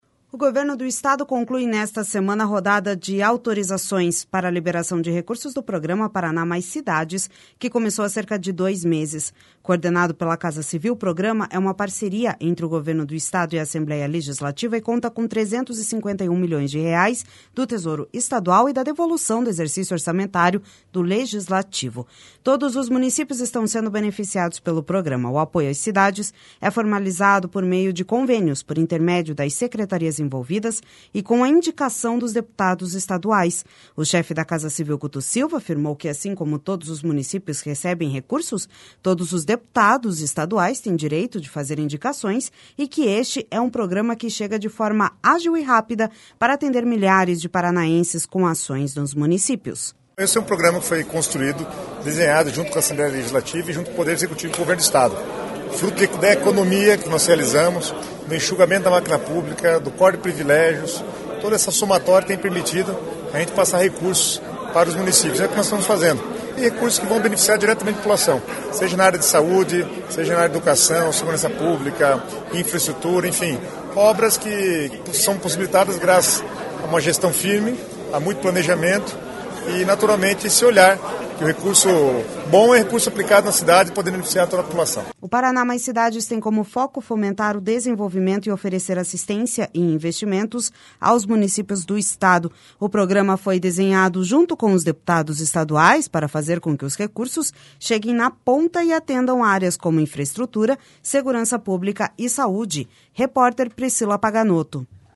O chefe da Casa Civil, Guto Silva, afirmou que assim como todos os municípios recebem recursos, todos os deputados estaduais têm direito de fazer indicações e que este é um programa que chega de forma ágil e rápida para atender milhares de paranaenses com ações nos municípios.// SONORA GUTO SILVA//O Paraná Mais Cidades tem como foco fomentar o desenvolvimento e oferecer assistência e investimentos aos municípios do Estado.